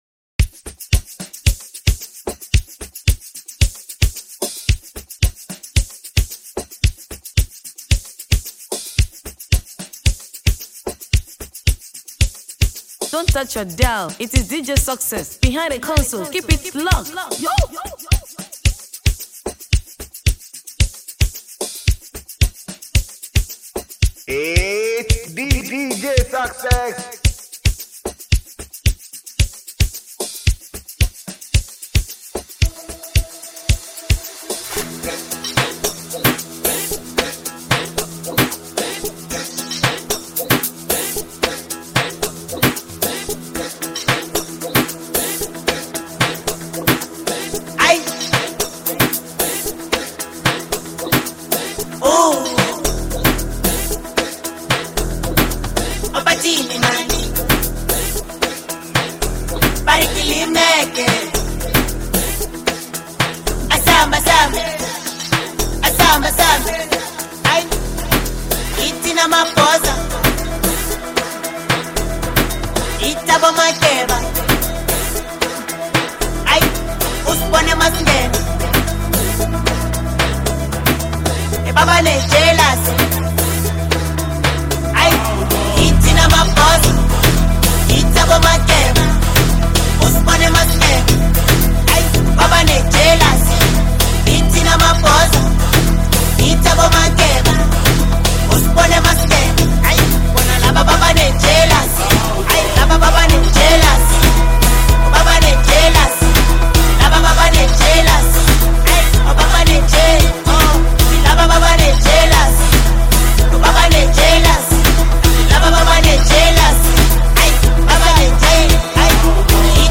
Ghanaian disc jockey